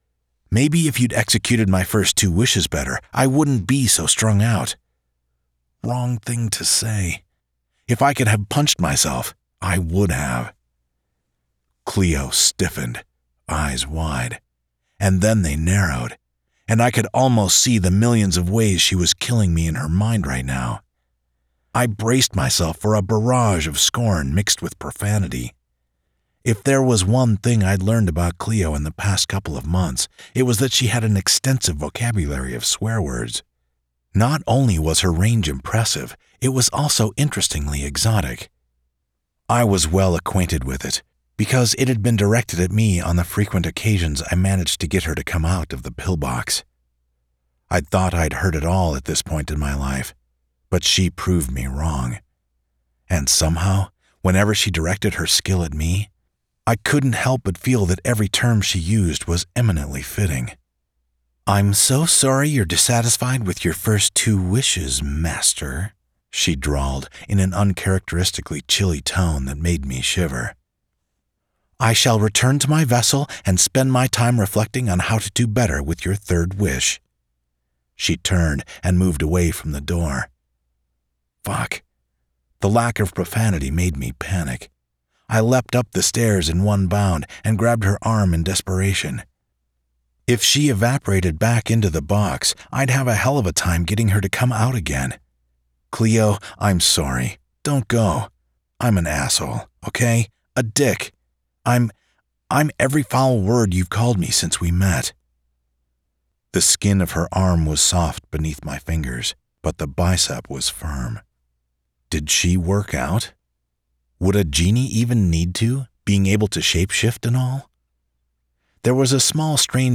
Audiobook Details
The-Third-Wish-Audiobook-Sample.mp3